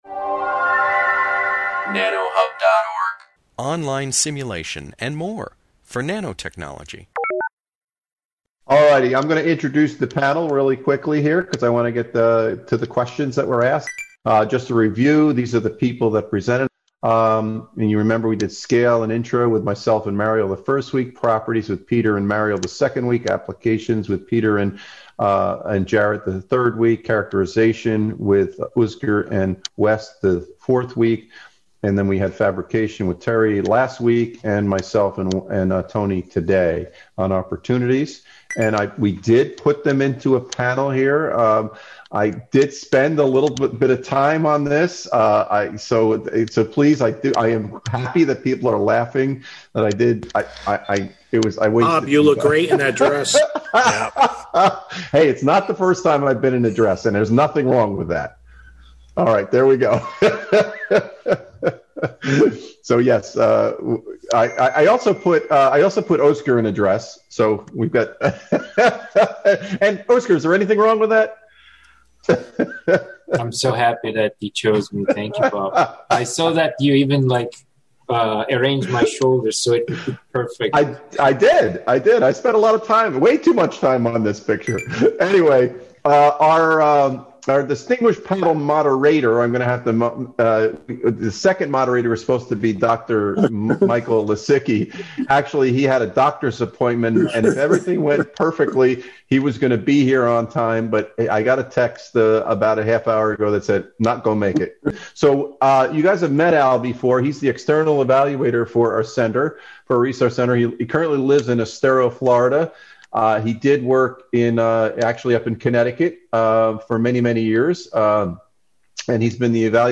NACK Center: Panel Discussion
This video, provided the Nanotechnology Applications and Career Knowledge Support (NACK) Center at Pennsylvania State University, features a panel of presenters from the 2021 NACK nanotechnology educators workshop series. Throughout the video, expert panelists discuss safe work practices in nanotechnology, nanotechnology curriculum from low grade levels to college, nano applications in medicine, recruiting and maintaining nanotechnology students, and job prospects in the nanotechnology industry.